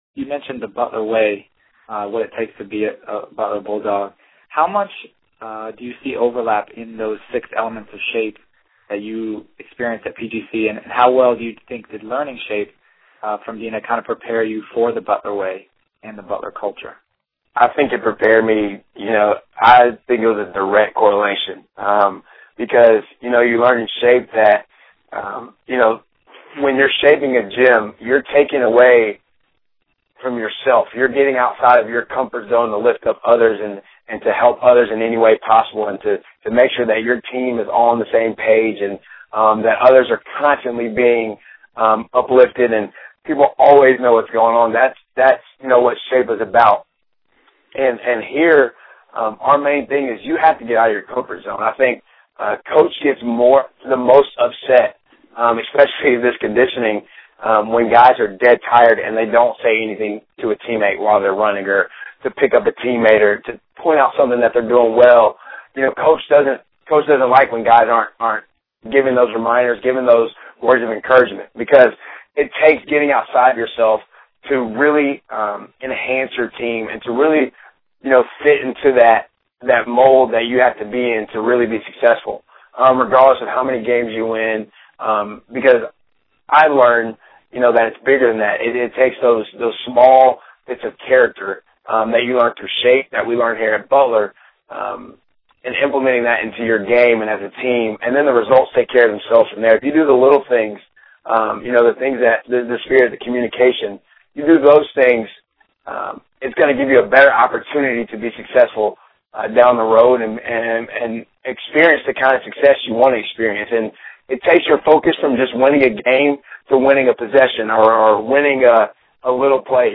My hope is that you all found and will find value in the PGC Online Training quarterly interviews with college athletes and coaches while you are in the midst of training in order to reach your basketball aspirations.